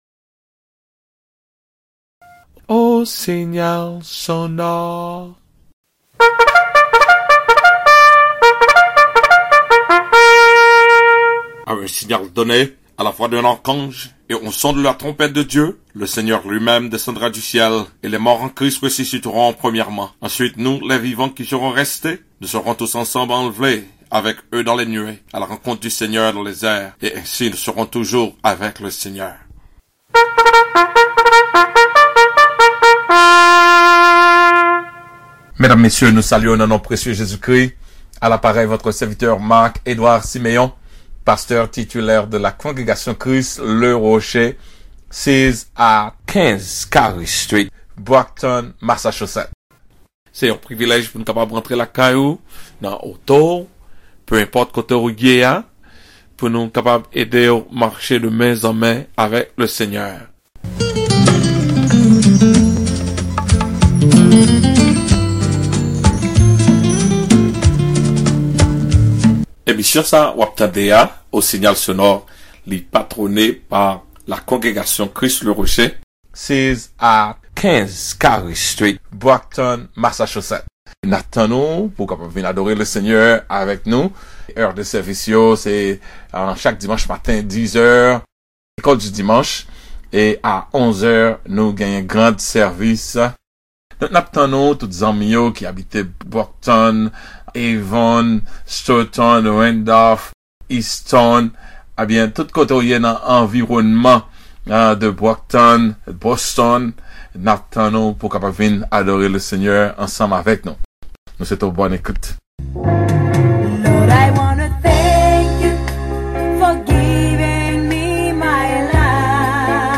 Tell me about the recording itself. CHRIST THE ROCK CONGREGATION Sunday